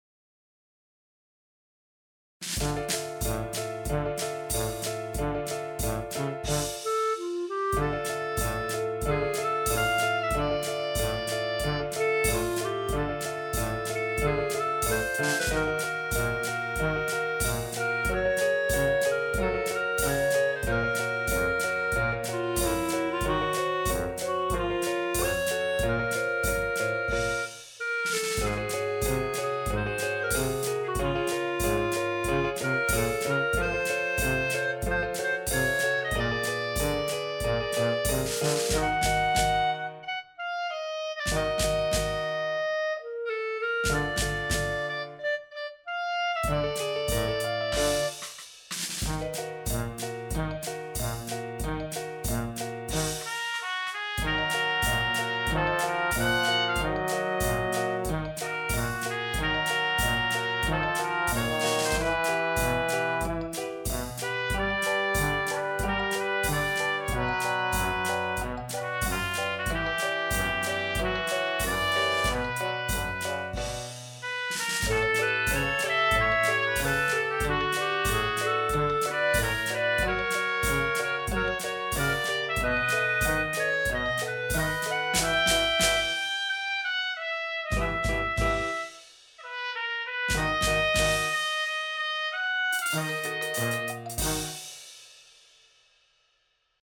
1’36 BPM: 94 Description
Complete arrangement